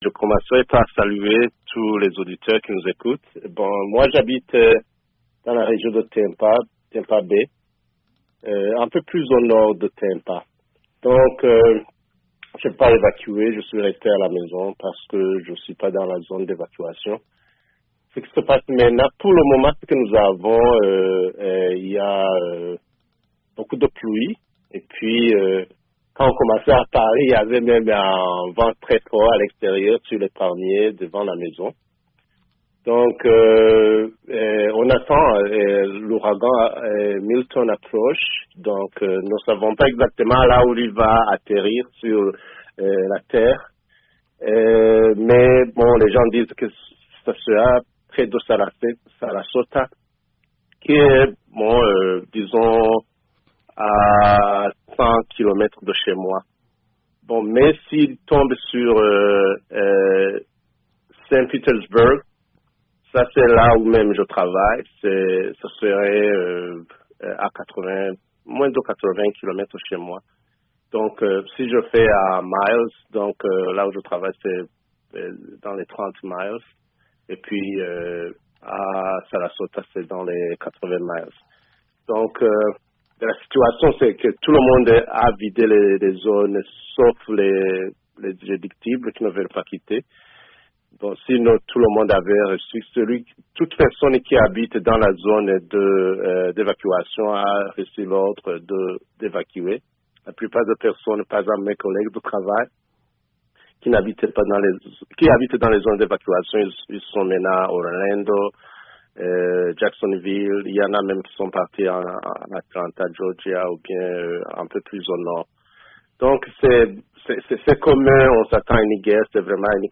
"Gardez-nous dans vos prières", dit à VOA Afrique un résidant de Floride